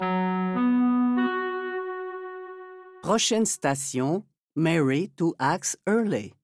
Listen to the métro voice pronounce the name Mary-Two-Axe-Earley